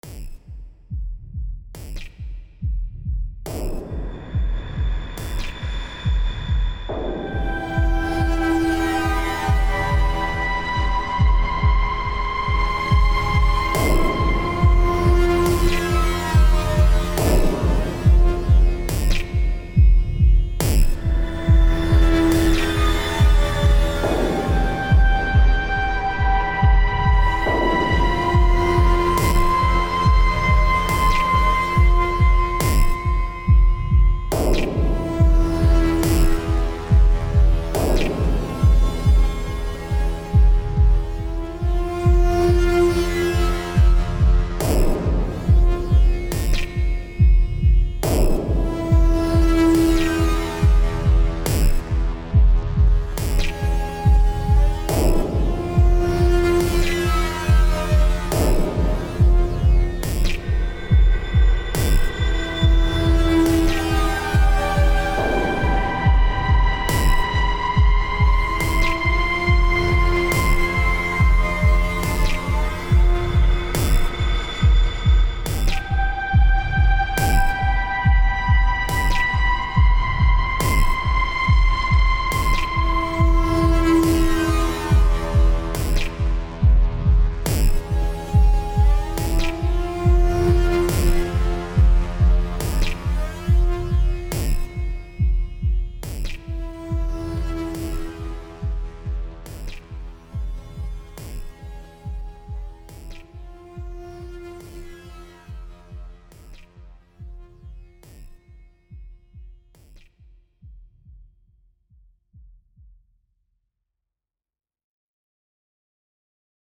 Dark Amience in this musictrack, usuable for danger scenes.